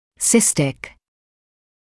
[‘sɪstɪk][‘систик]кистозный; относящийся к цисте